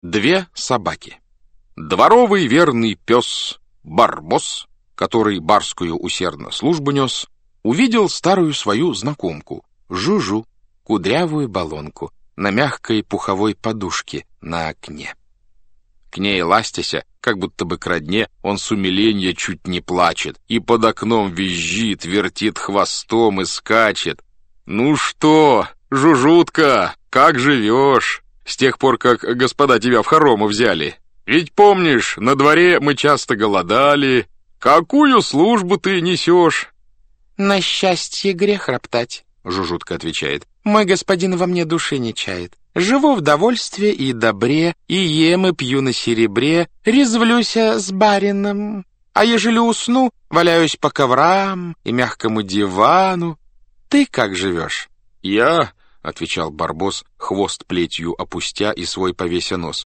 Aудиокнига Самые смешные басни Автор Иван Крылов Читает аудиокнигу Александр Клюквин.